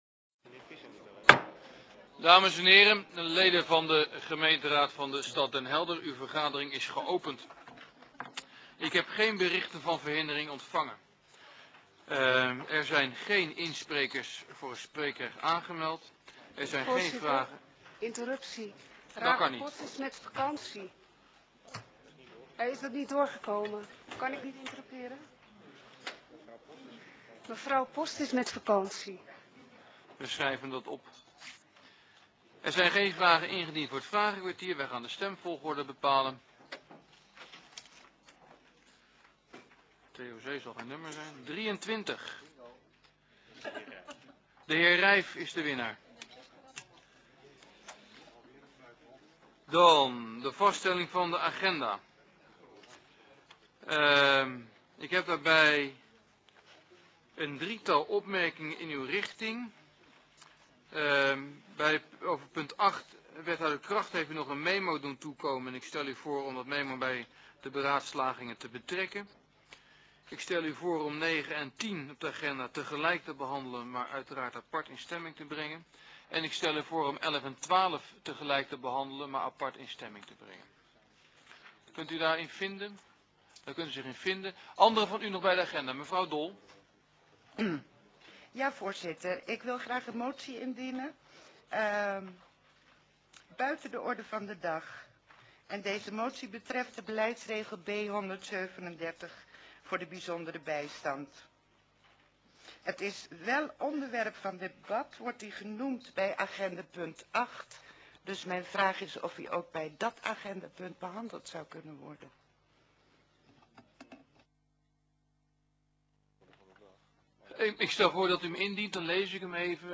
Vergadering van de raad van de gemeente Den Helder op 29 oktober 2007 om 21.30 uur in het stadhuis van Den Helder